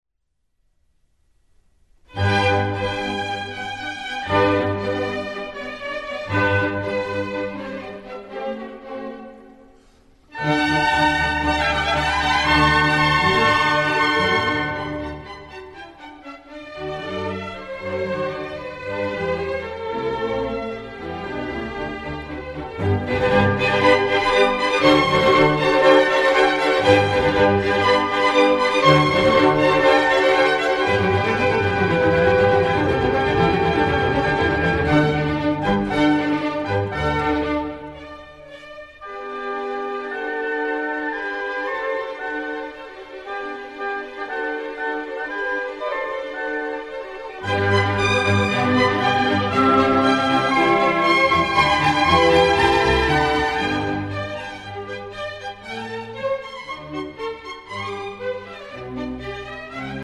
Wolfgang Amadeus Mozart - Concerto no. 3 in G major, K. 216 - 1. Allegro